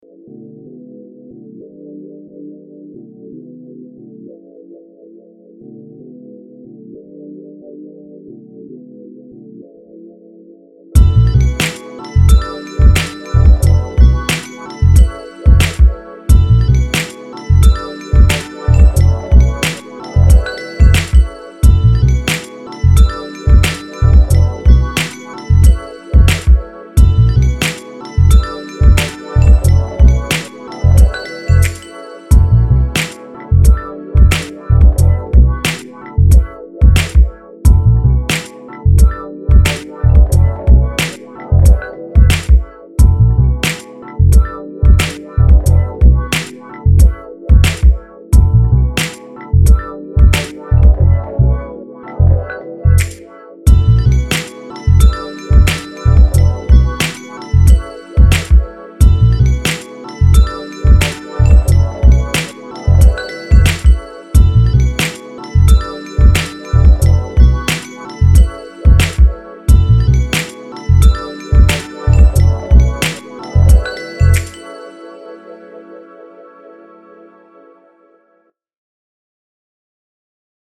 I just did a quick, albeit messy one levels wise, in Digitakt.
everything is from the sample including drums.
Only outside element is a bass tone.
Dreamy vibes, loved the sample usage
Nice chill vibes